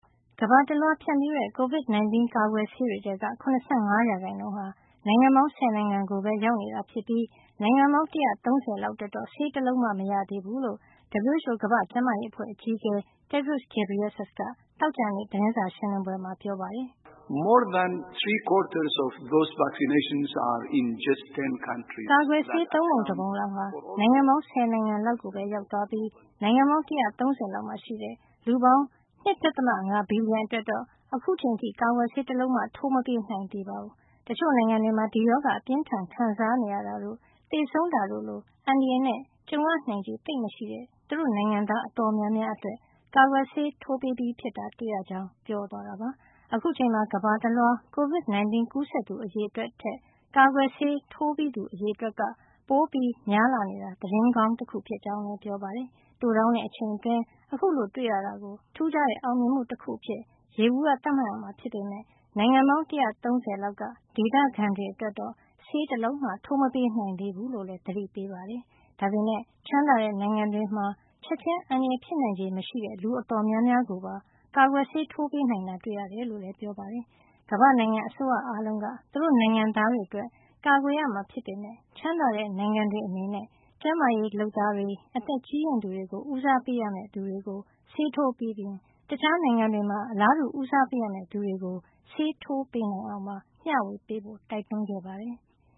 ကမ္ဘာတလွှား ဖြန့်ဖြူးတဲ့ Covid 19 ကာကွယ်ဆေးတွေထဲက ၇၅ % ဟာ နိုင်ငံပေါင်း ၁၀ နိုင်ငံကိုပဲ ရောက်နေတာ ဖြစ်ပြီး နိုင်ငံပေါင်း ၁၃၀ လောက်အတွက်တော့ ဆေးတလုံးမှ မရသေးဘူးလို့ WHO ကမ္ဘာ့ကျန်းမာရေးအဖွဲ့ အကြီးအကဲ Tedros Adhanom Ghebreyesus က သောကြာနေ့ ဂျနီဗာ သတင်းစာရှင်းလင်းပွဲမှာ ပြောပါတယ်။